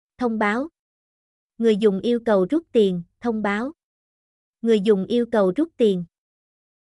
notification-withdrawal-R8_2LC6I.mp3